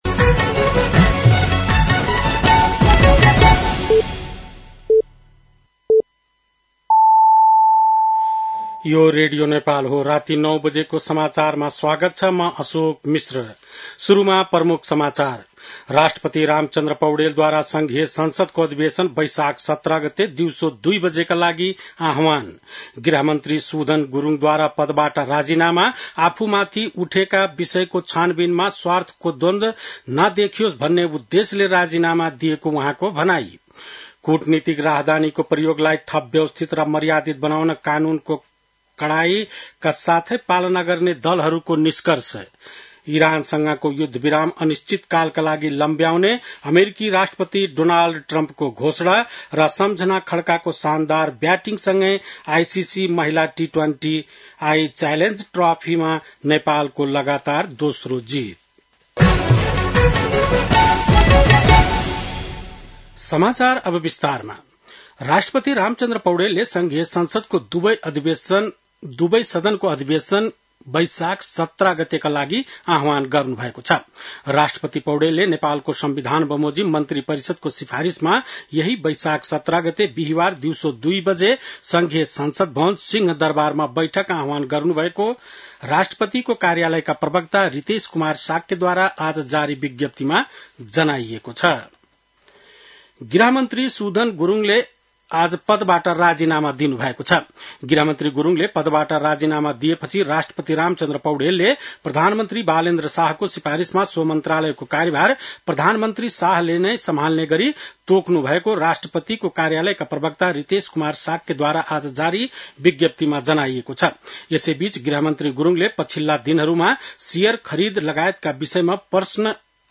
बेलुकी ९ बजेको नेपाली समाचार : ९ वैशाख , २०८३